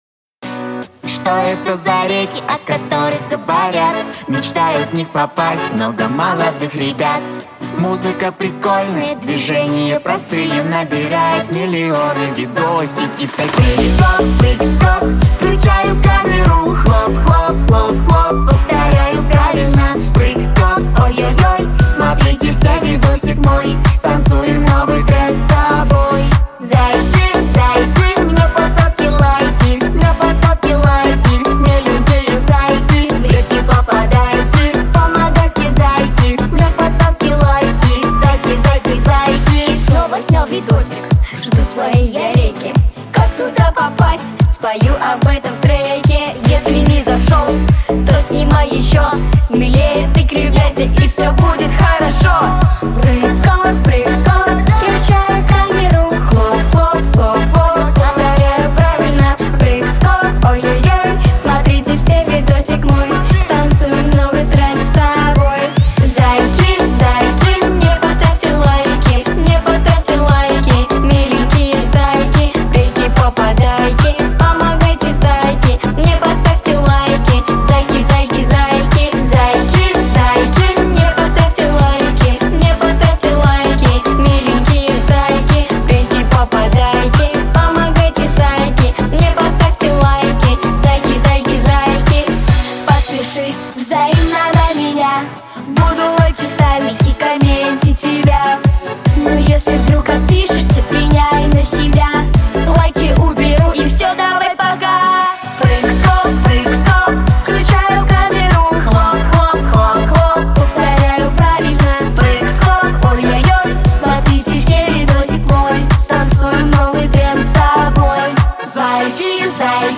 • Категория: Детские песни
танцевальная, детская дискотека
Детская музыкальная группа